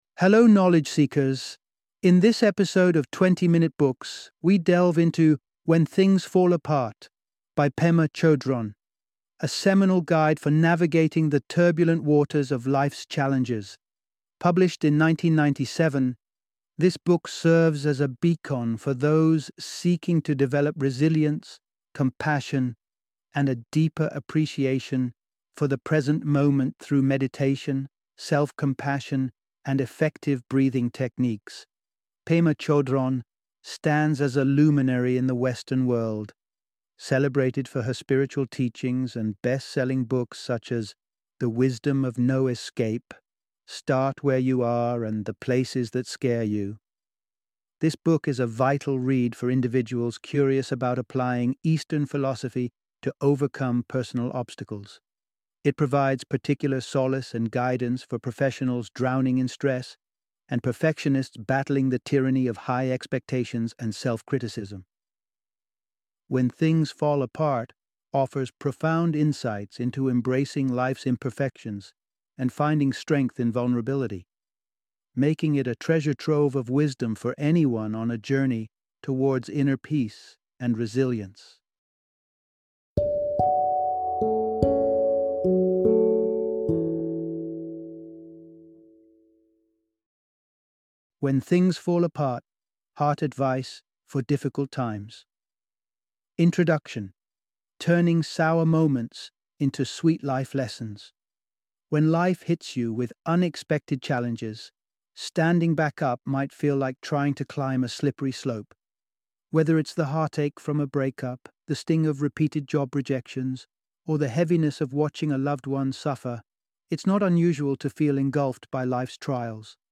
When Things Fall Apart - Audiobook Summary